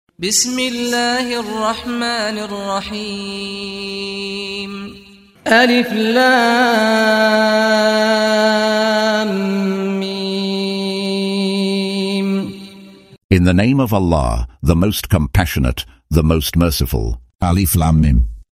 Audio version of Surah Al-Imran ( The Family of Imran ) in English, split into verses, preceded by the recitation of the reciter: Saad Al-Ghamdi.